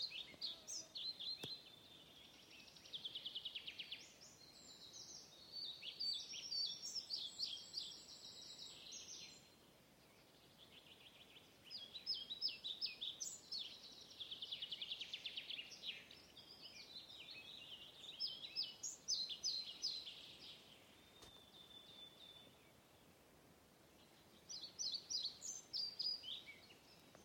мухоловка-пеструшка, Ficedula hypoleuca
Administratīvā teritorijaValkas novads
СтатусПоёт